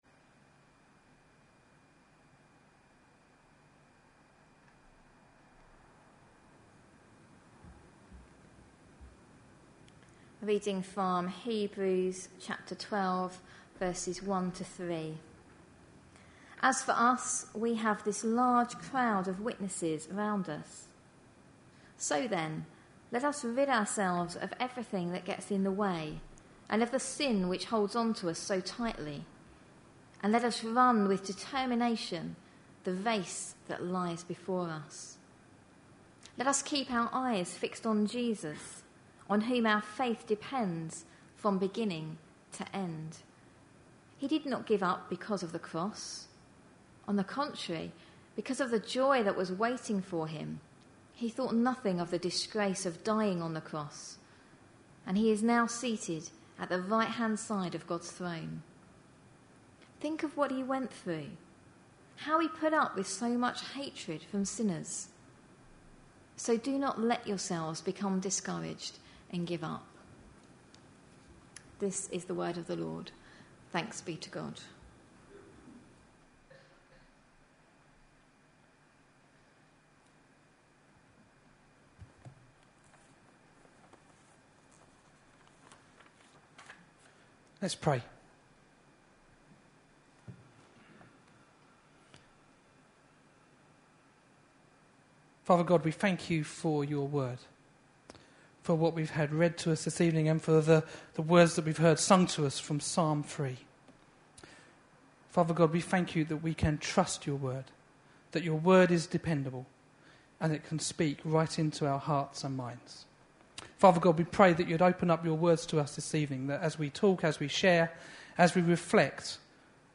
A sermon preached on 29th July, 2012, as part of our Olympic Ideals series.